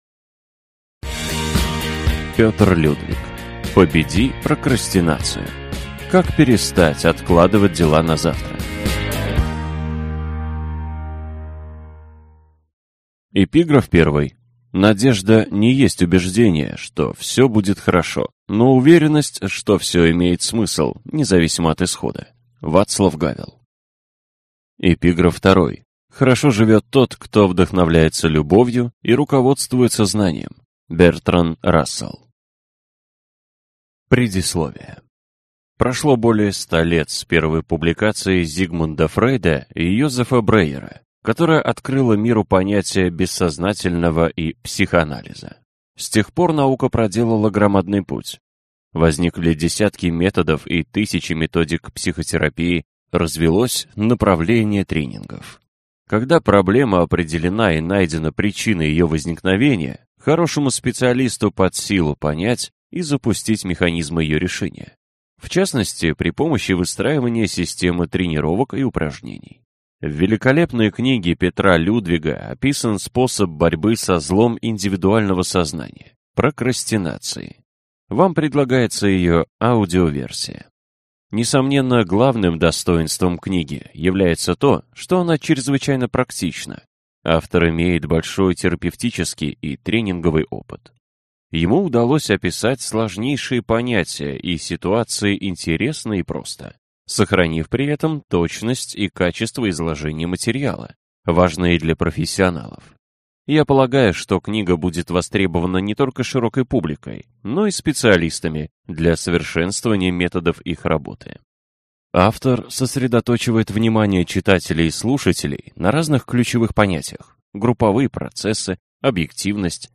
Аудиокнига Победи прокрастинацию! Как перестать откладывать дела на завтра - купить, скачать и слушать онлайн | КнигоПоиск